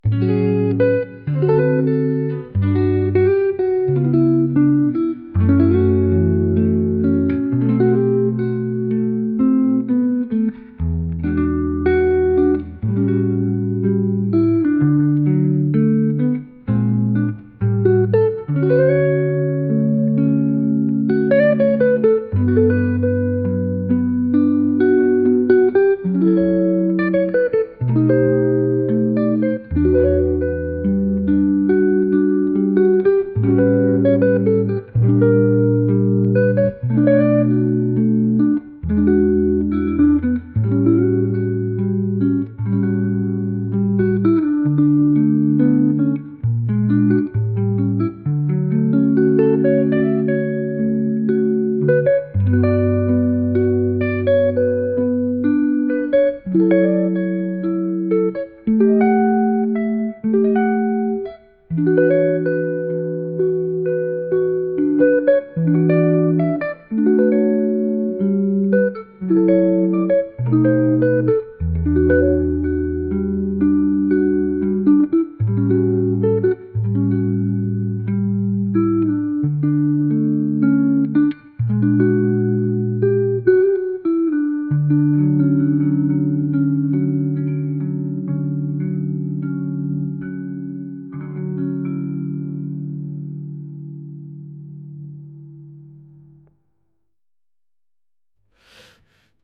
ゆったりとしたフレーズが流れるギター曲です。